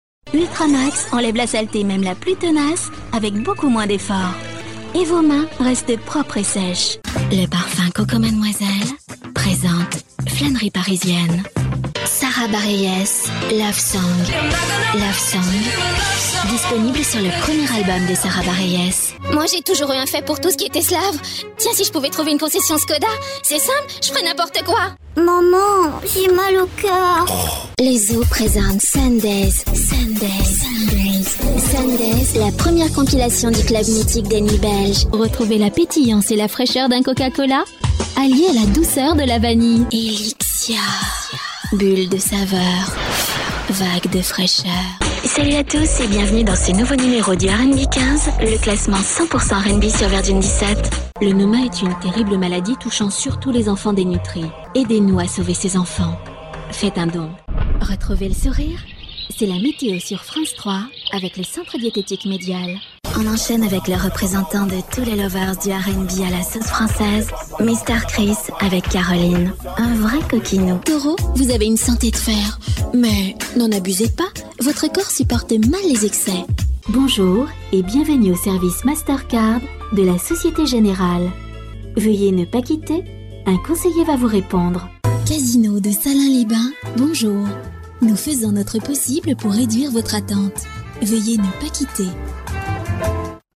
Comédienne , voix off depuis 1983